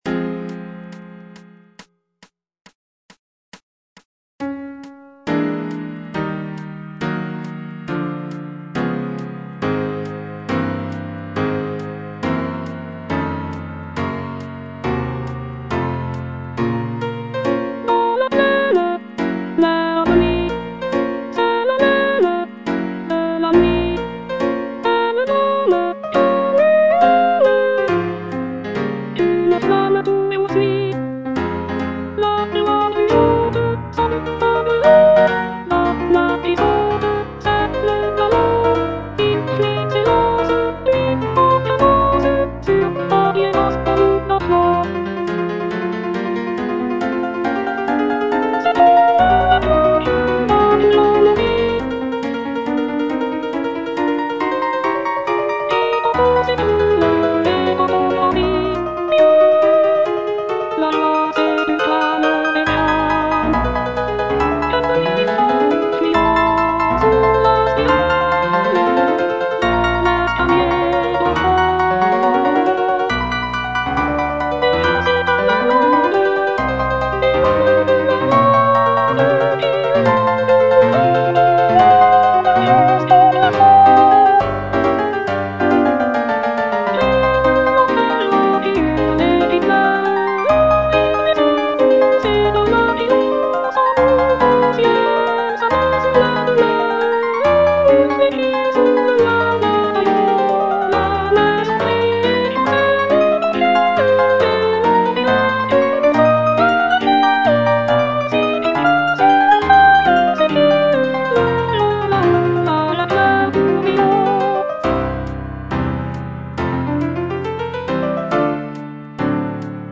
G. FAURÉ - LES DJINNS Soprano I (Voice with metronome) Ads stop: auto-stop